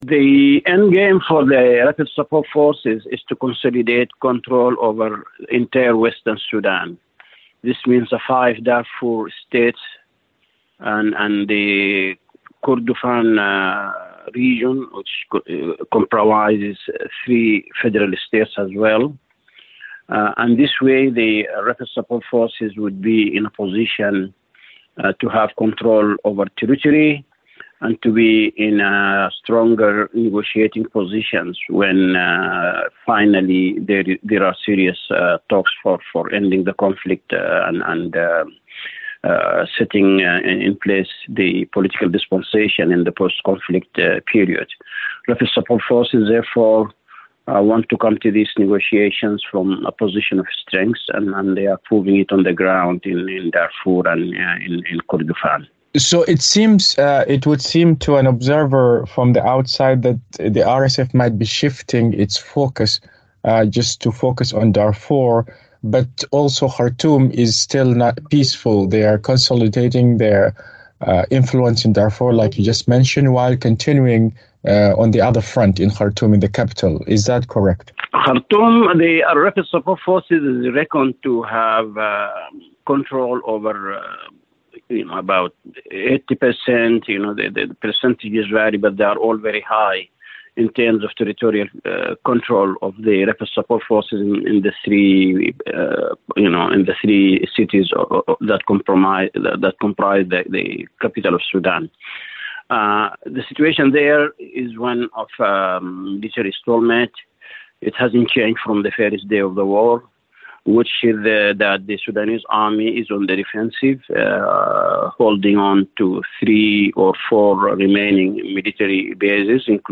Analysis: RSF Gains Control in Darfur Amidst International Concern [5:32]